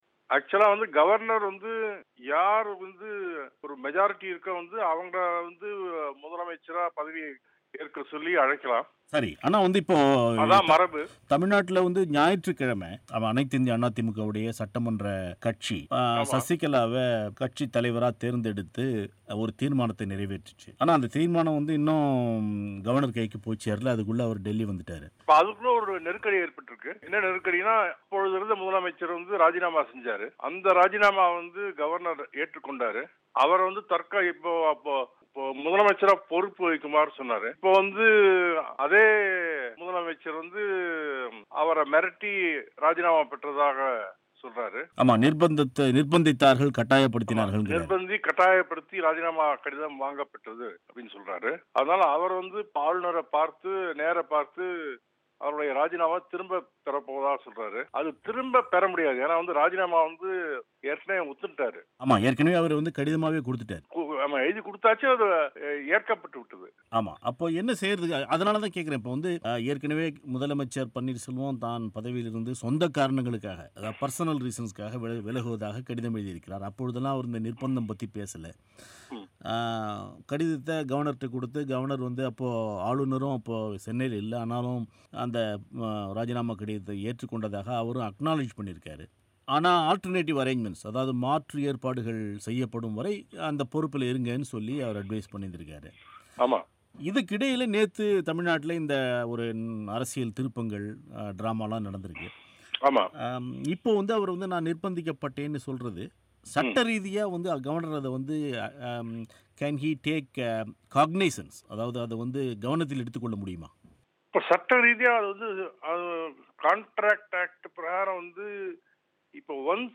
ராஜிநாமா கடிதத்தை ஓ.பன்னீர்செல்வம் திரும்பப் பெற முடியுமா? மோகன் பராசரன் பேட்டி